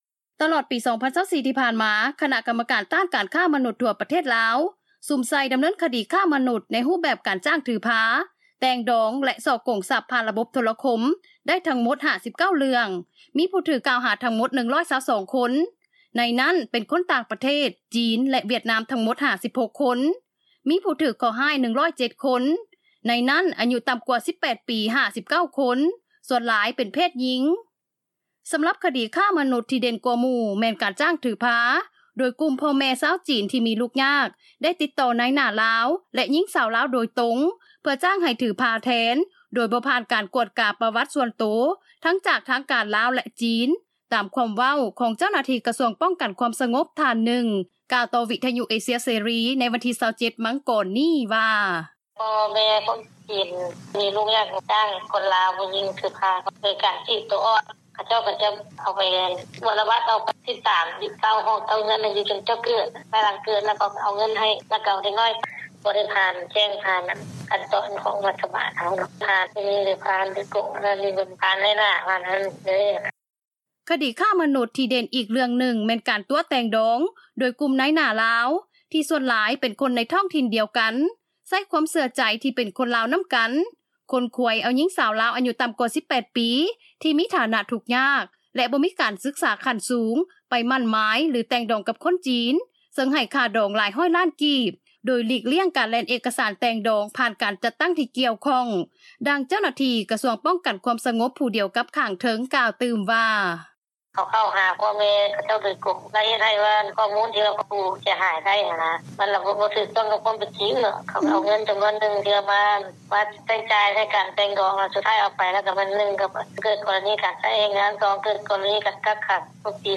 ດັ່ງເຈົ້າໜ້າທີ່ ສູນໃຫ້ຄໍາປຶກສາ ແລະ ປົກປ້ອງແມ່ຍິງ-ເດັກນ້ອຍ ນາງໜຶ່ງ ກ່າວວ່າ: